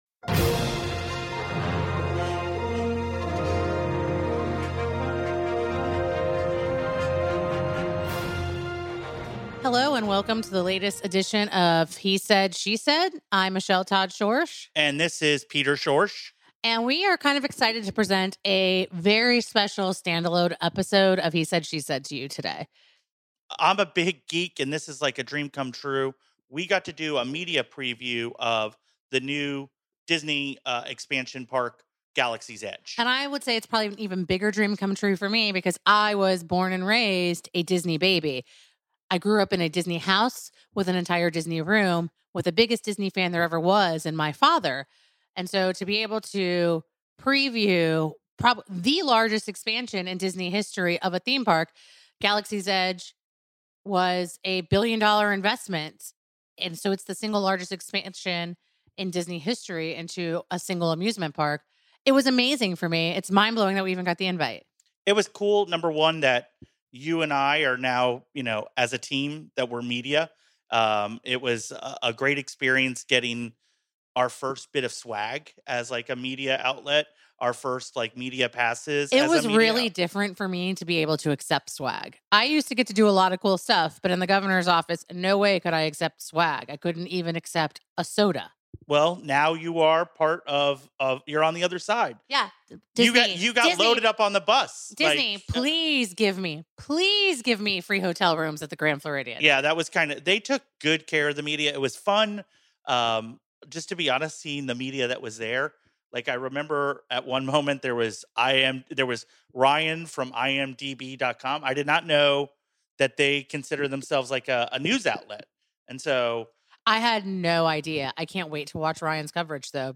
Touring Disney's highly anticipated new attraction, including an interview